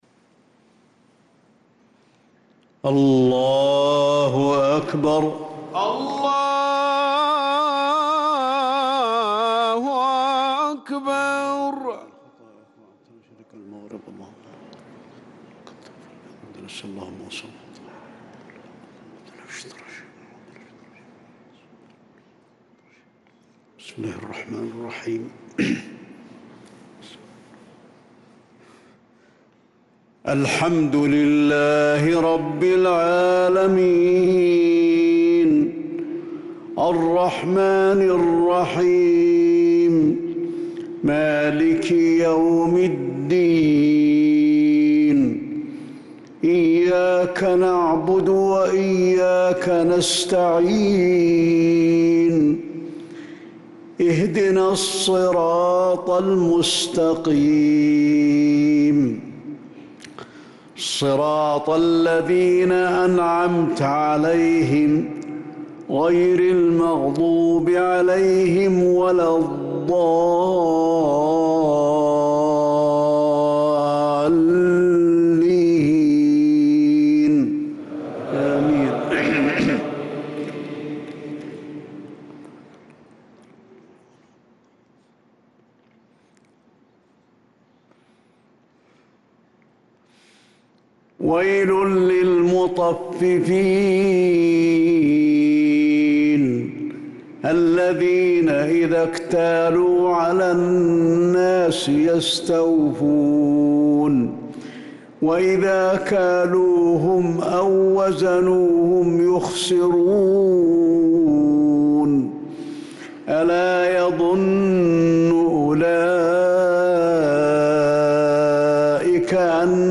صلاة الفجر للقارئ علي الحذيفي 19 ذو القعدة 1445 هـ
تِلَاوَات الْحَرَمَيْن .